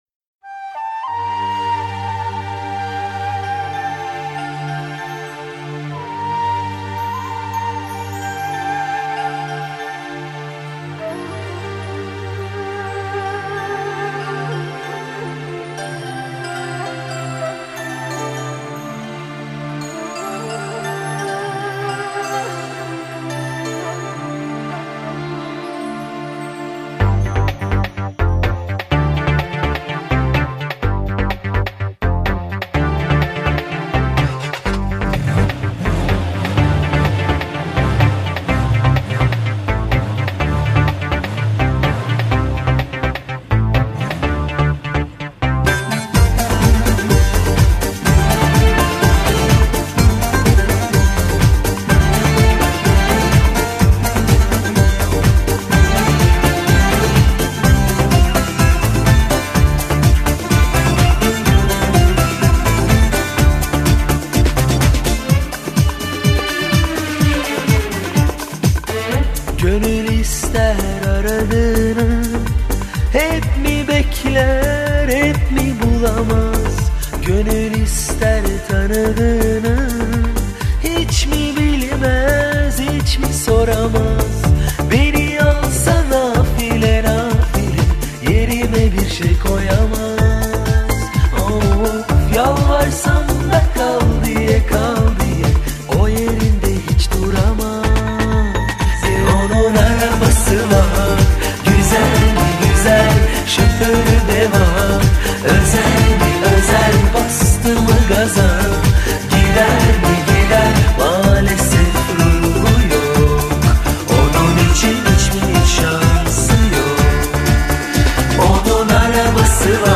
Категория: Восточная музыка » Турецкие песни